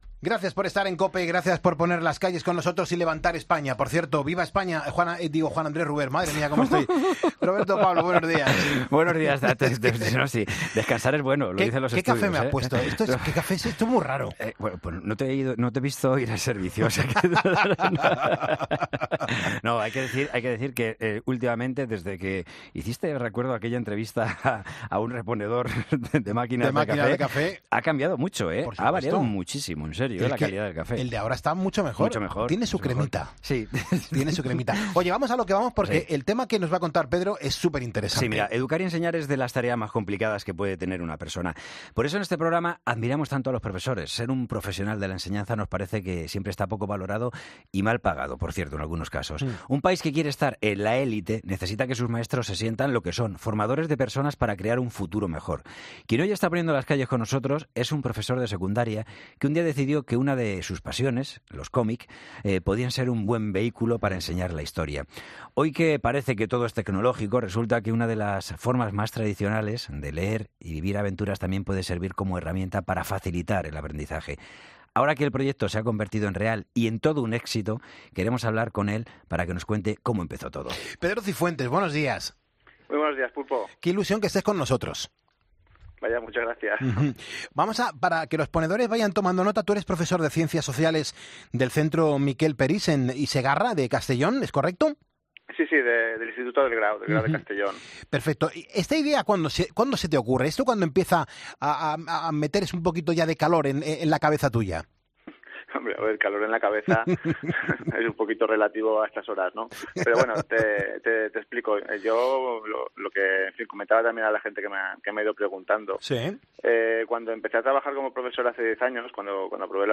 Quien hoy está poniendo las calles con nosotros es un profesor de secundaria que un día decidió que una de sus pasiones, los cómic, podían ser un buen vehículo para enseñar la historia. Hoy que parece que todo es tecnológico, resulta que una de las formas más tradicionales de leer y vivir aventuras también puede servir como herramienta para facilitar el aprendizaje.